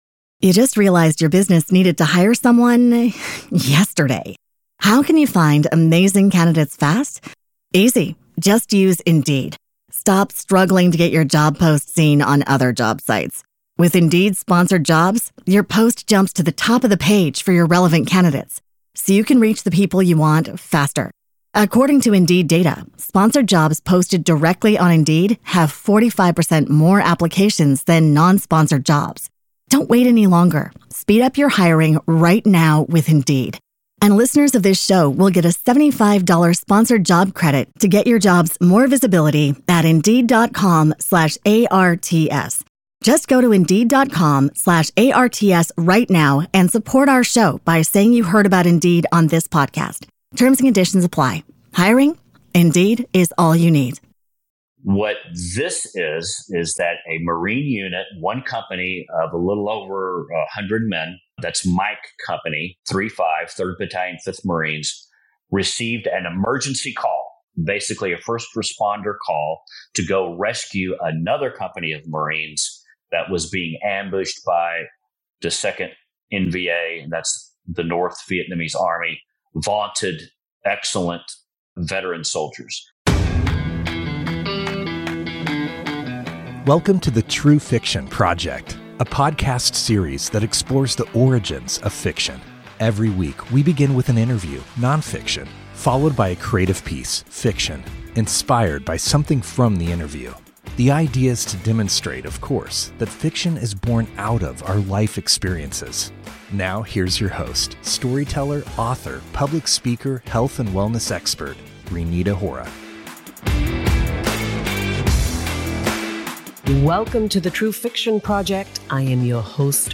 Tune in for an unforgettable conversation about history, healing, and the importance of telling the truth.